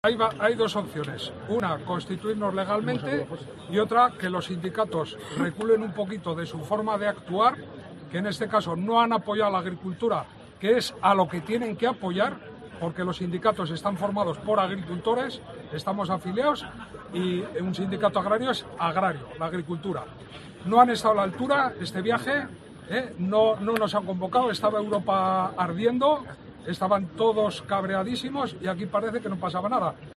uno de los agricultores concentrados en el Polígono de El Sequero